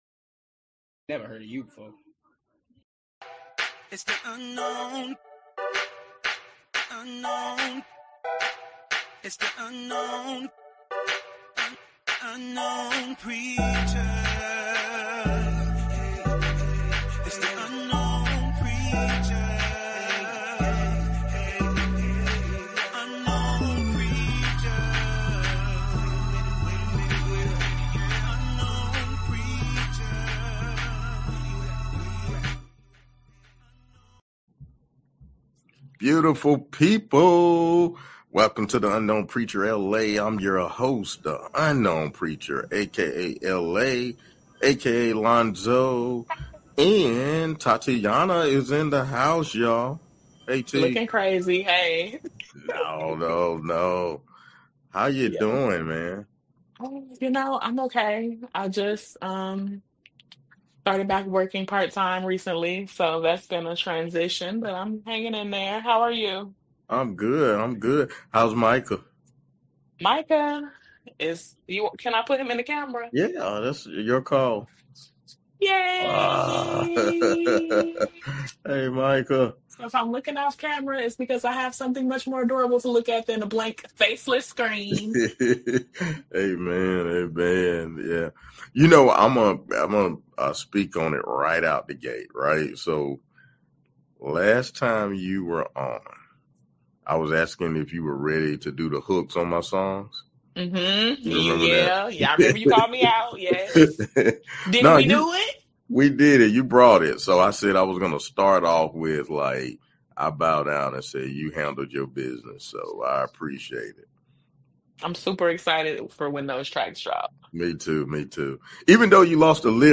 interviews Gospel Artist